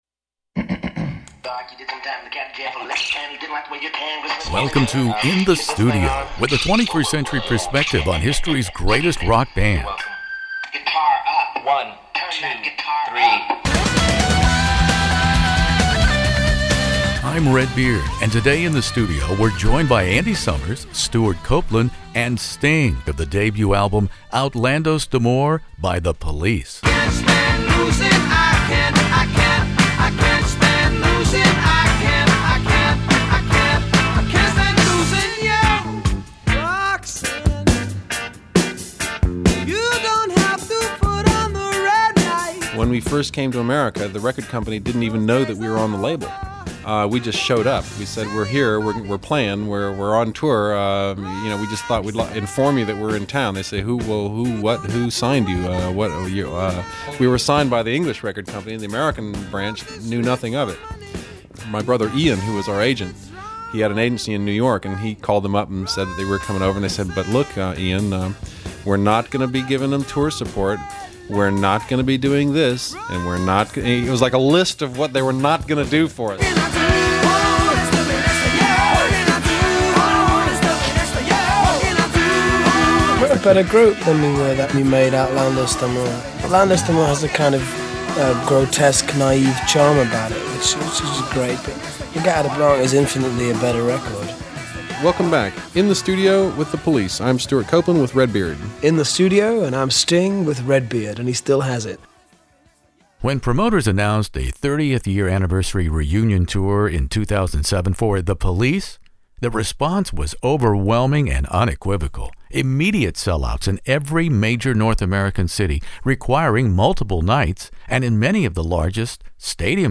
The Police Outlandos d'Amour interview with Sting, Stewart Copeland, Andy Summers In the Studio